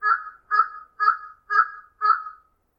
cuervo4
crow4.mp3